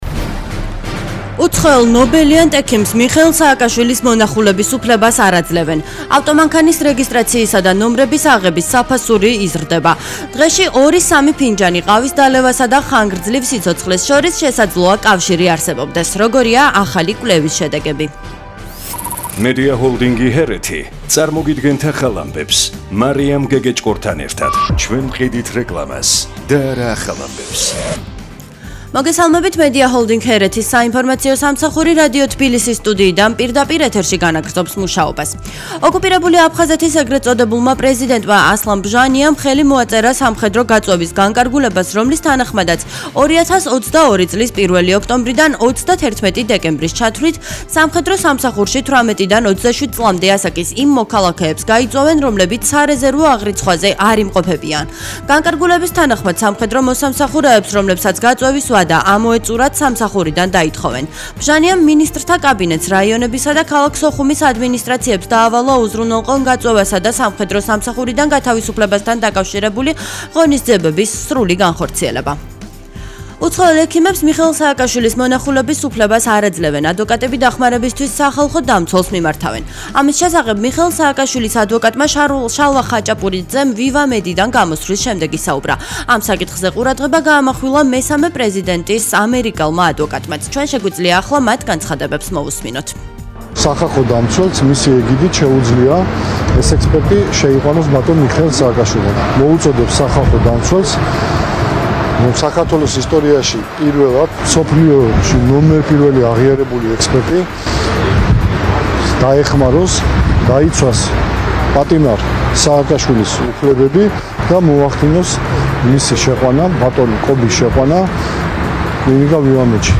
ახალი ამბები 15:00 საათზე
News-15.00.mp3